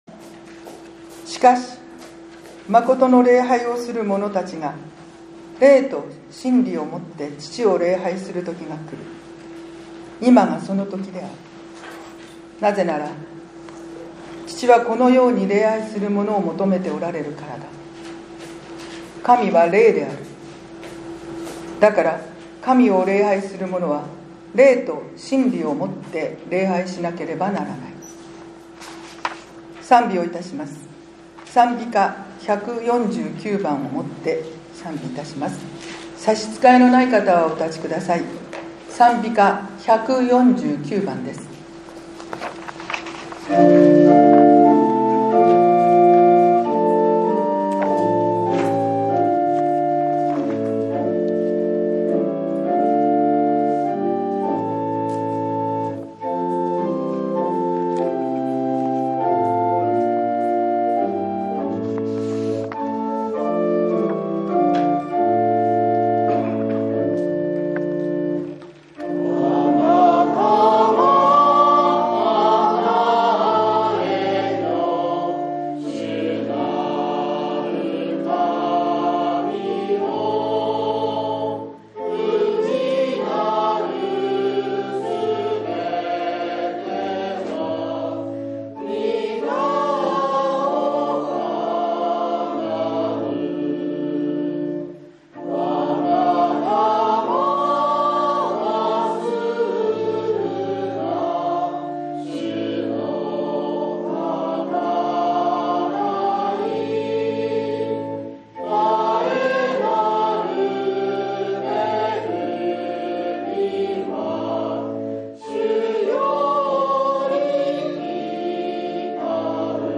６月８日（日）ペンテコステ礼拝